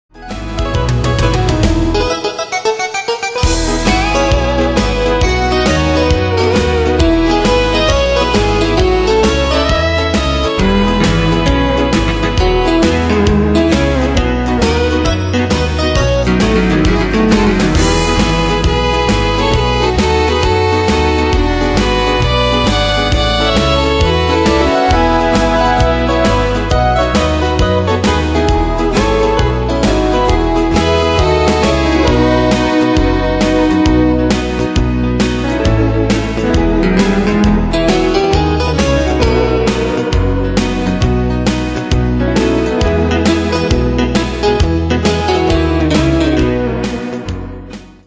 Pro Backing Tracks